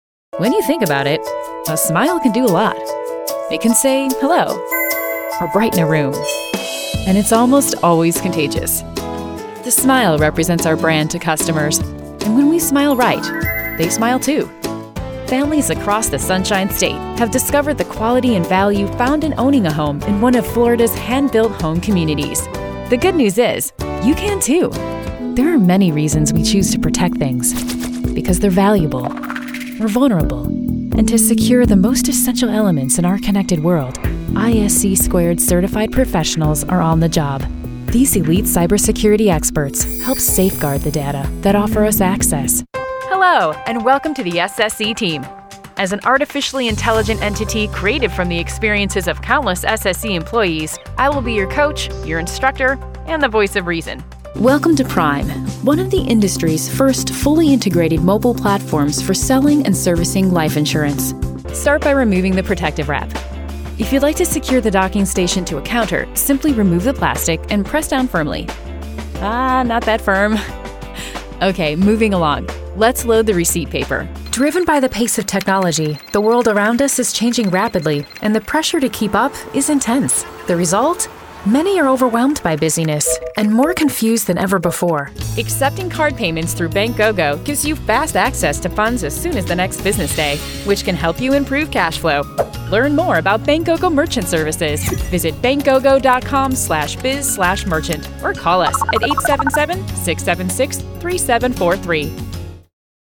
Authoritative, down to earth, smart, warm, and witty with a dash of sarcasm and sass
Explainer
English (North American)
My studio is equipped with a WhisperRoom, Neumann TLM 103, Focusrite 2i2 Preamp and ProTools.